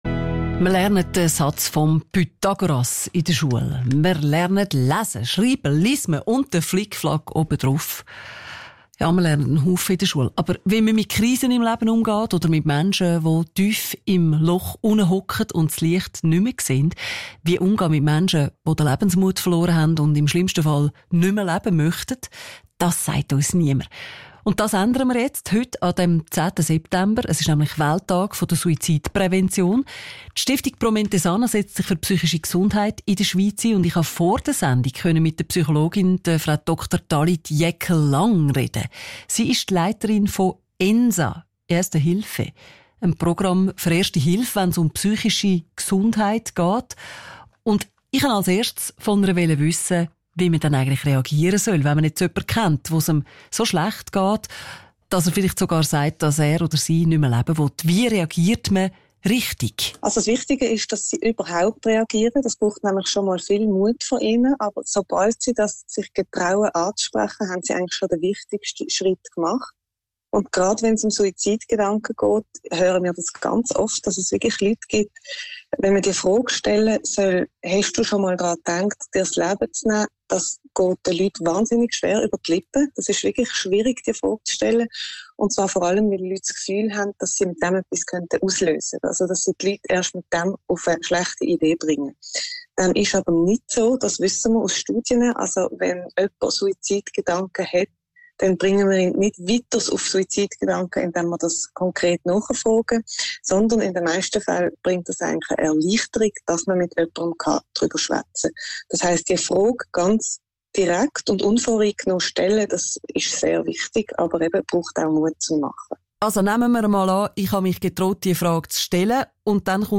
Zum Radio-Interview vom 10.09.2024